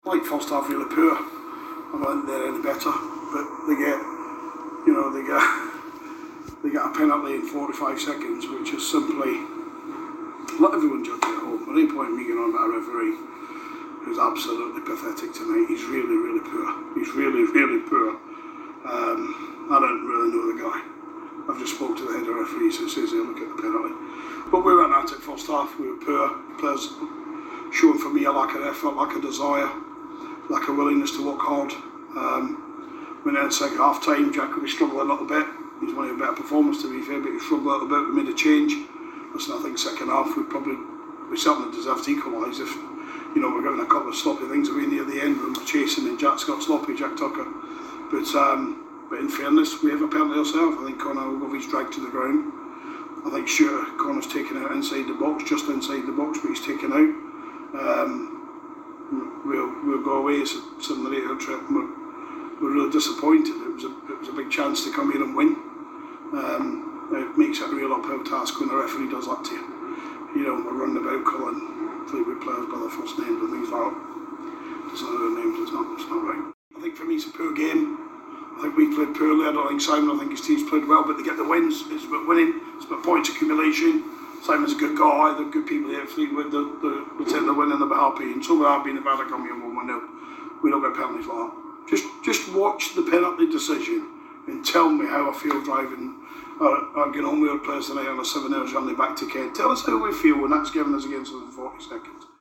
LISTEN: Gillingham manager Steve Evans has been reacting to their 1-0 defeat at Fleetwood Town - 10/03/2021